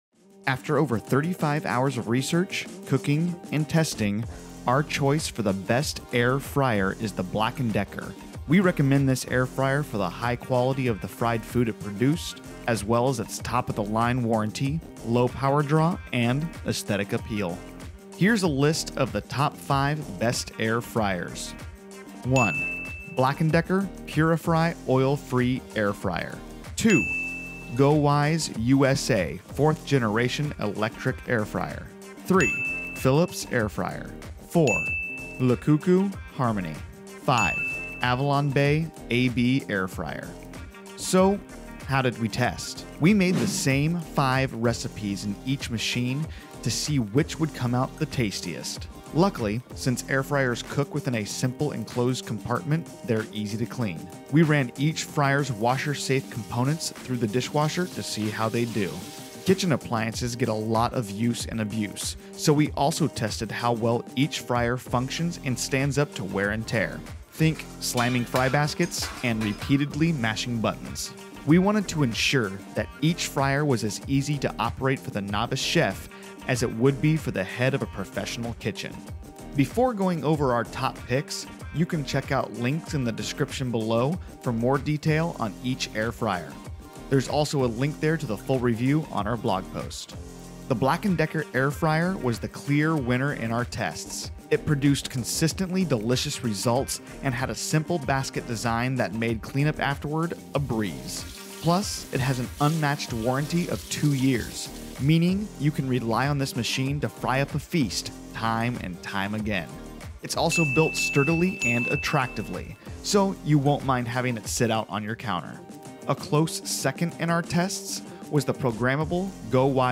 Male
Yng Adult (18-29), Adult (30-50)
Narration
Singing
Explainer Videos
All our voice actors have professional broadcast quality recording studios.